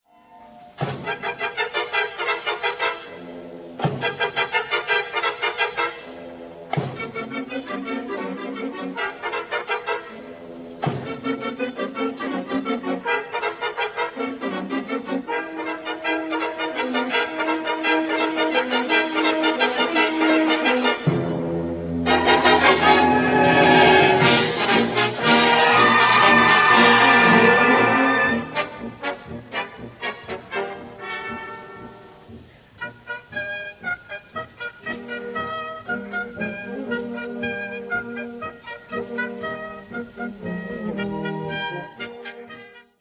Based on a Valencian folk melody first introduced in flutes.